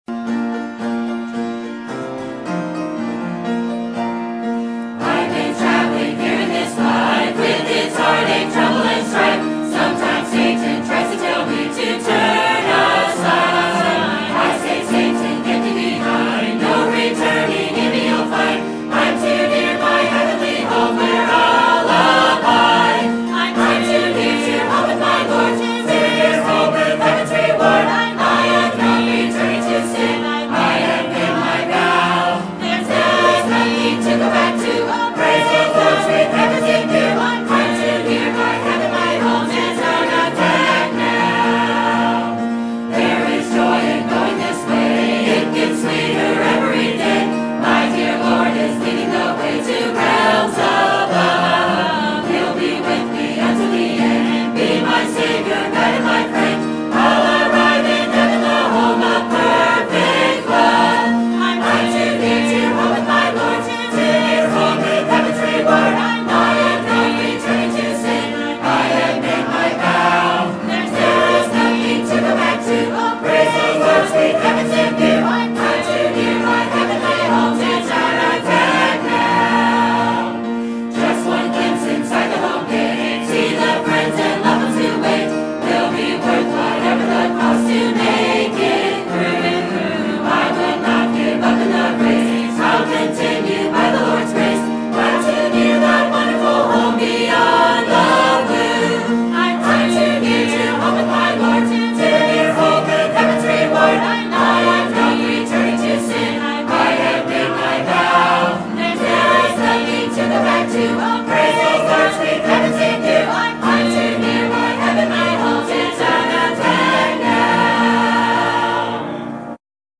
Sermon Topic: General Sermon Type: Service Sermon Audio: Sermon download: Download (23.99 MB) Sermon Tags: Leviticus Principles Mingle Separation